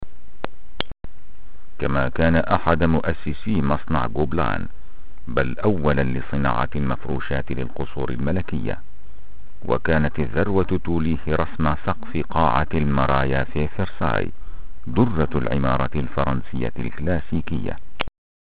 voix grave ,lecture documentaire doublage series ,spot pub.arabe letteraire sans accent.
Sprechprobe: Sonstiges (Muttersprache):
arabian male voice artist littere